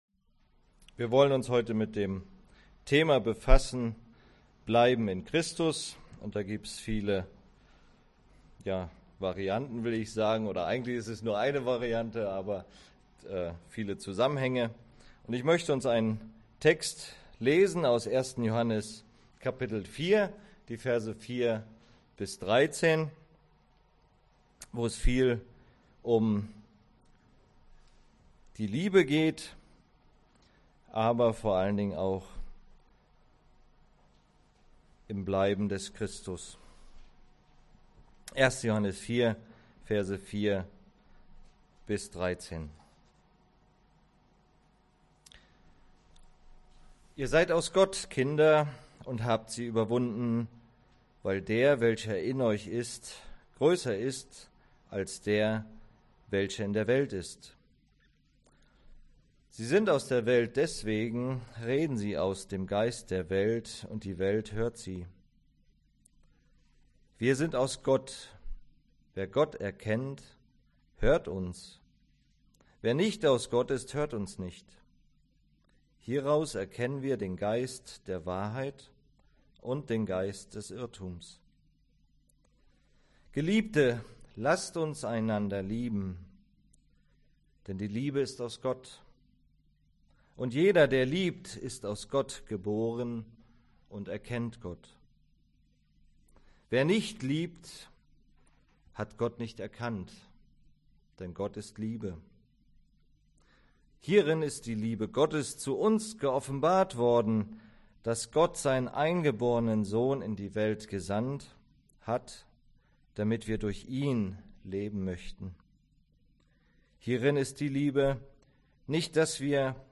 Predigt Gemeinde